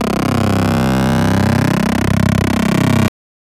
Notch filter on bass
example of successfull notch filtering
notch.wav